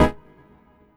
17CHORD01 -L.wav